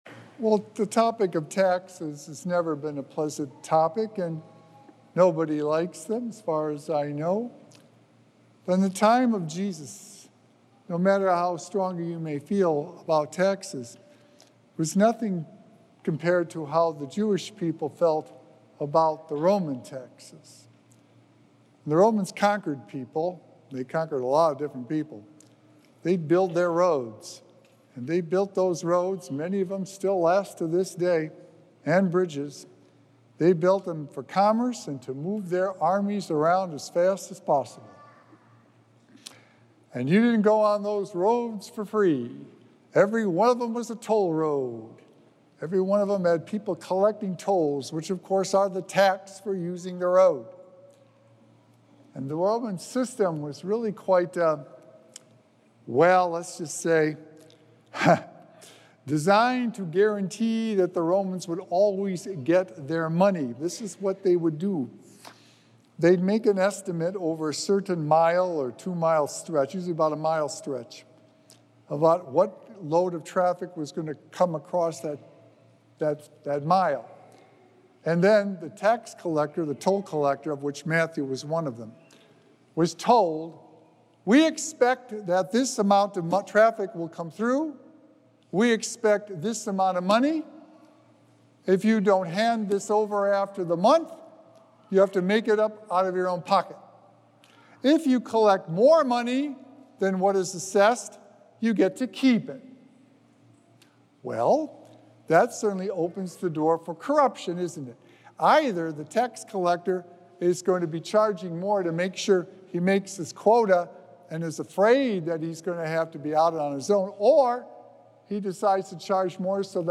Sacred Echoes - Weekly Homilies Revealed